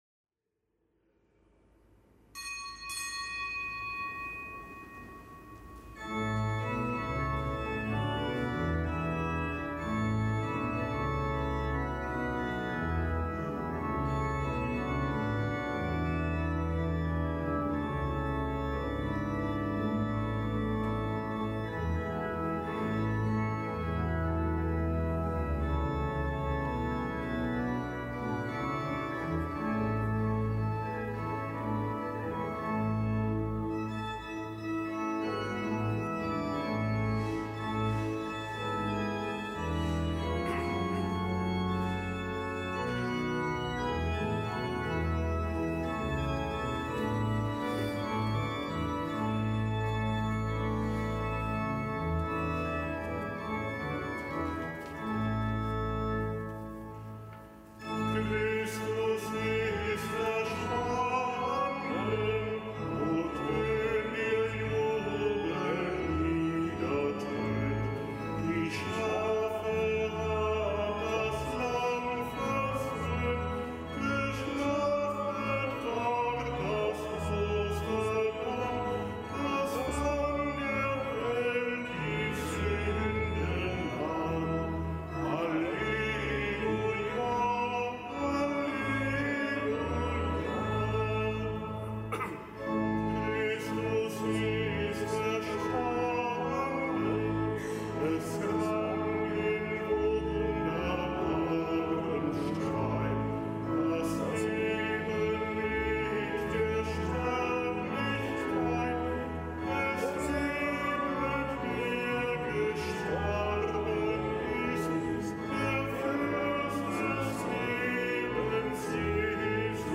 Joh 7,40-53 - Gespräch